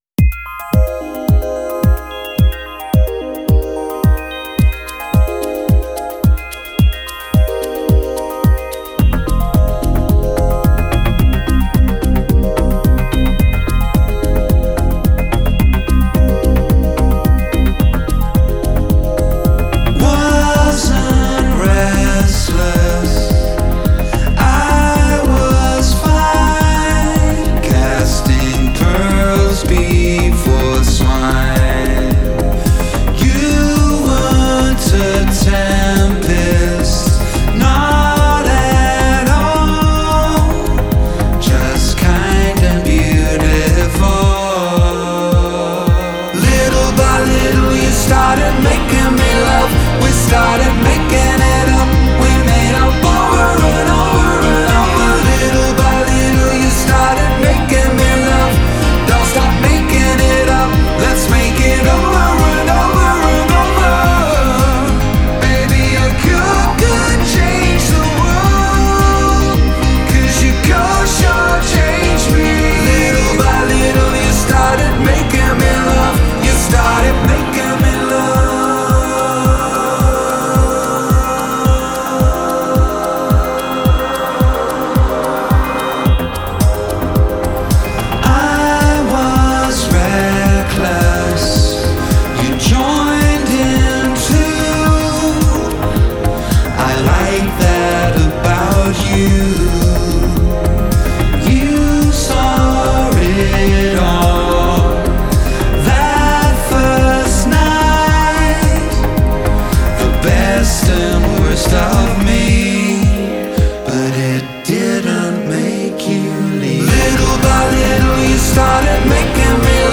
поп-музыка
рок-музыка